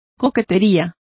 Complete with pronunciation of the translation of coquetry.